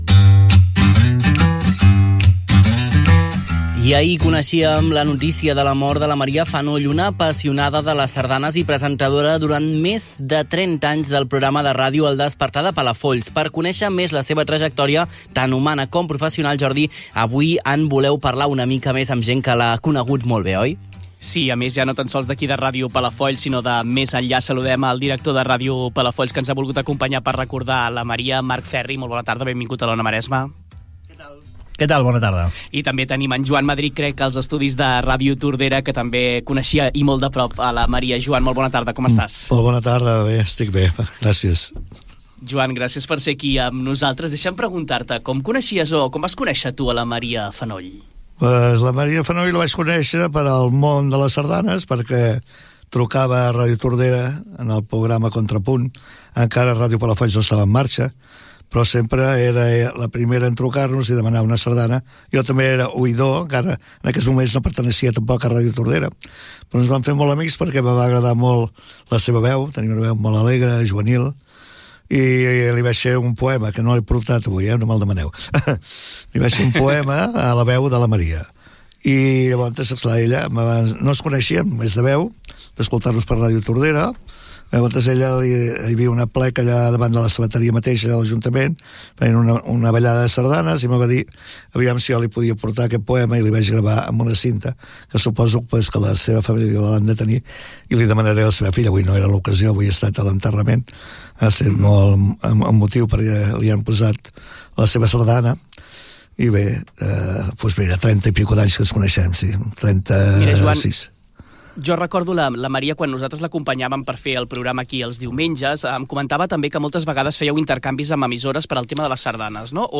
Comiat del programa i sardana. Gènere radiofònic Entreteniment